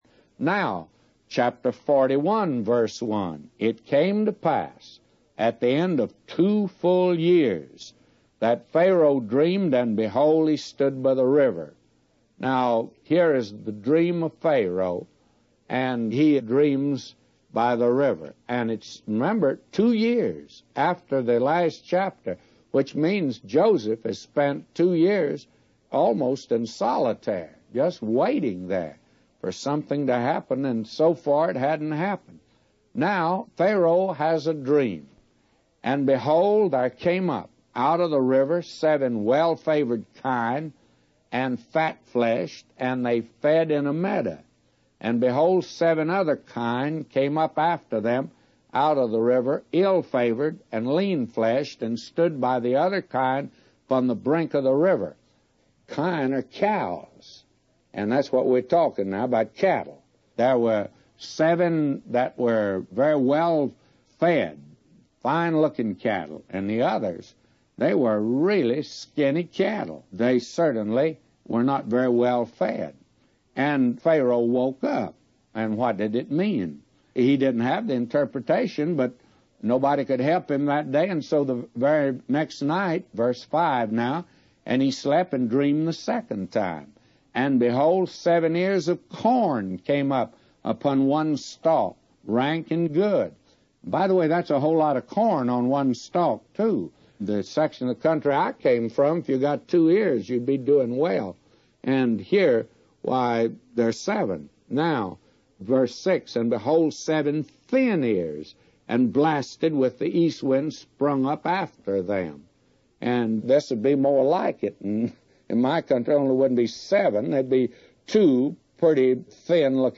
A Commentary By J Vernon MCgee For Genesis 41:1-999